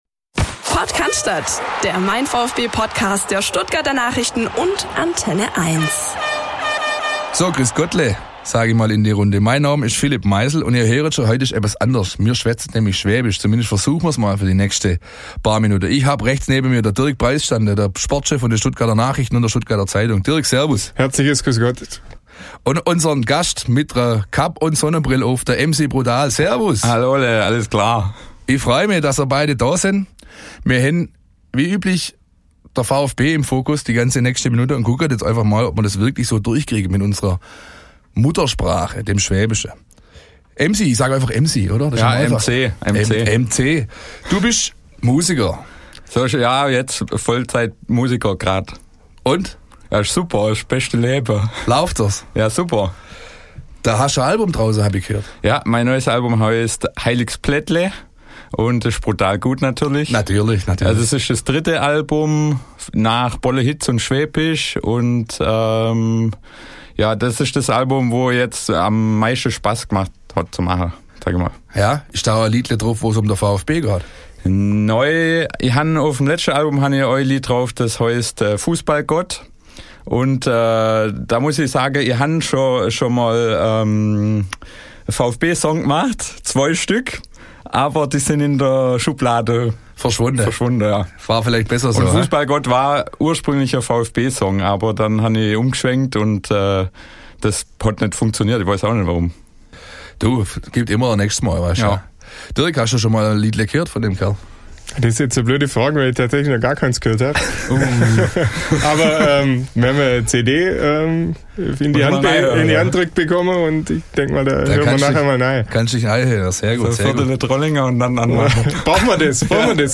Alles auf Schwäbisch